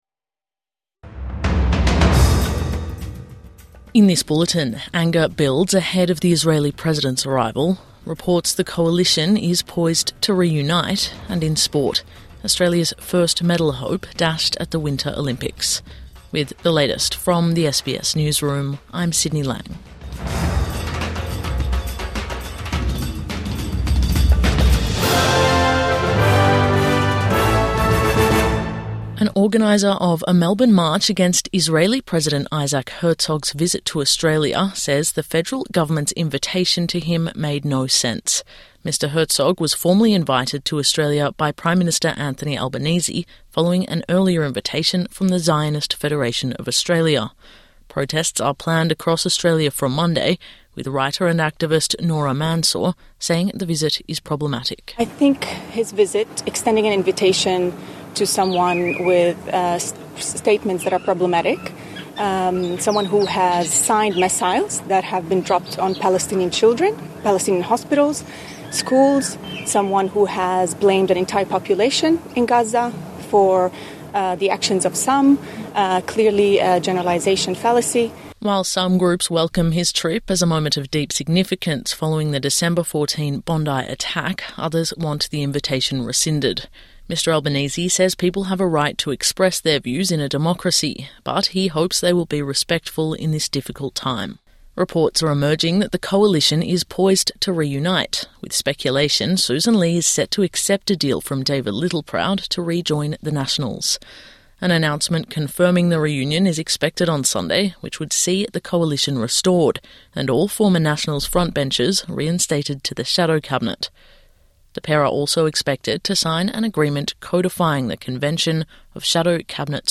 Anger builds ahead of the Israeli president's arrival | Midday News Bulletin 8 February 2026